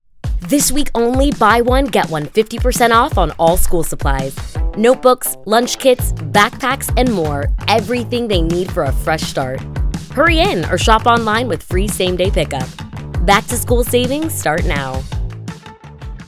Warm, polished, and engaging voiceover talent with a background in theater & law
Commercial - Back-To-School Savings, Energetic, Upbeat, Retail